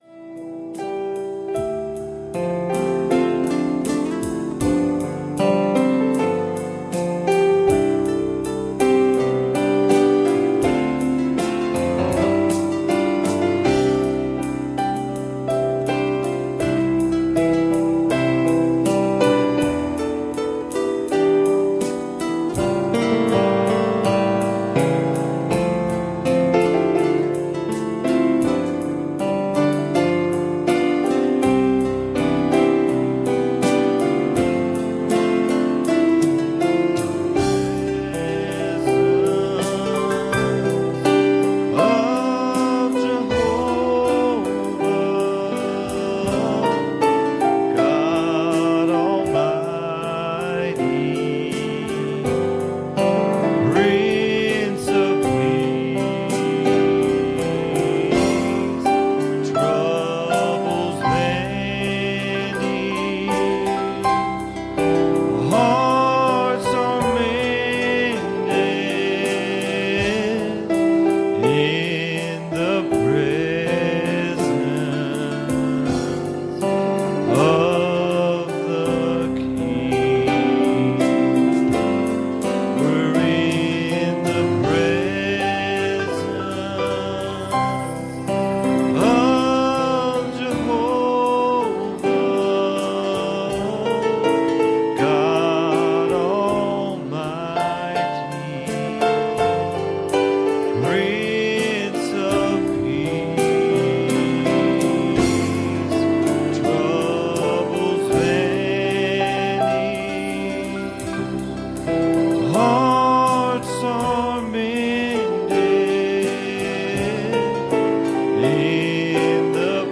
Passage: Ephesians 4:14 Service Type: Sunday Evening